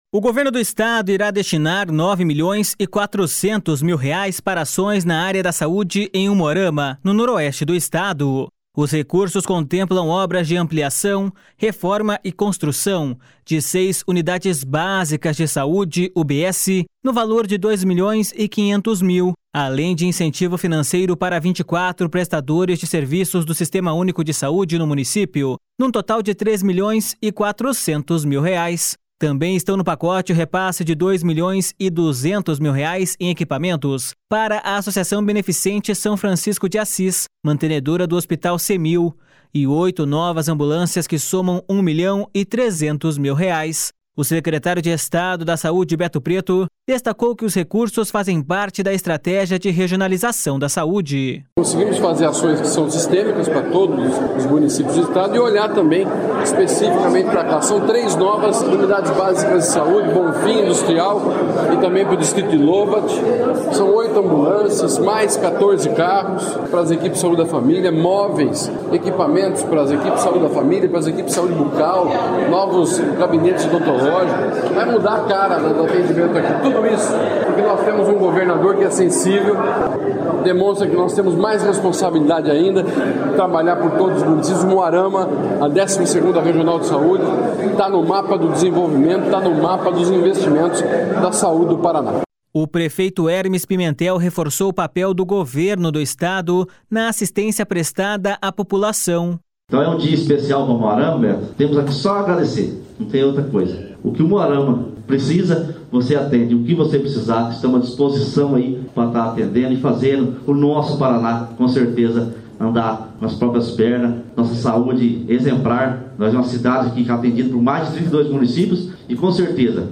O secretário de Estado da Saúde, Beto Preto, destacou que os recursos fazem parte da estratégia de regionalização da saúde.// SONORA BETO PRETO.//
O prefeito Hermes Pimentel reforçou o papel do Governo do Estado na assistência prestada à população.// SONORA HERMES PIMENTEL.//